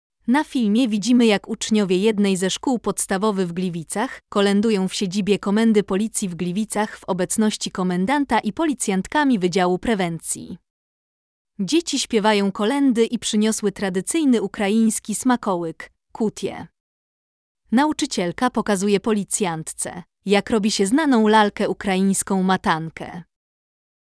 Młodzi artyści, działający w szkolnym kółku, przygotowali krótki program, w którym znalazły się tradycyjne ukraińskie kolędy oraz krótkie opowieści o zwyczajach bożonarodzeniowych pielęgnowanych w ich rodzinnych domach.
• Dzieci w sali komendy śpiewają kolendy
• Przedstawienie dzieci śpiewających kolędy w sali komendy